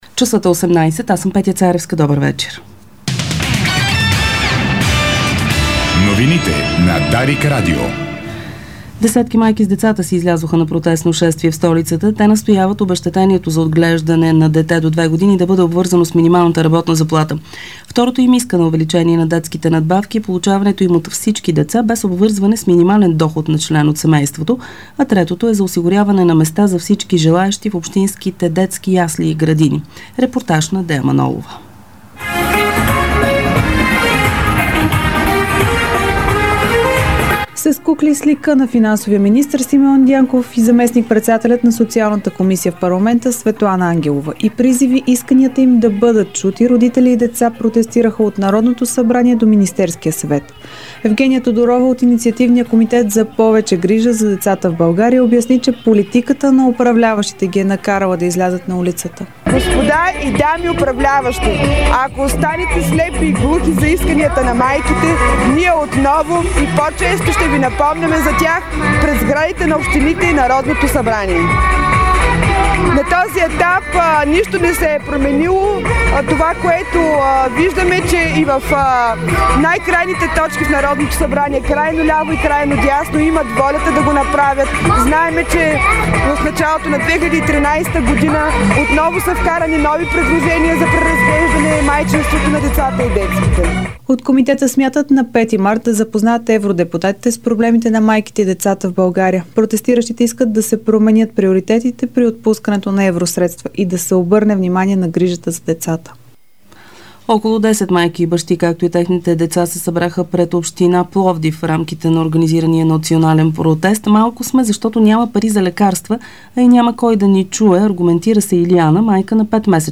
Обзорна информационна емисия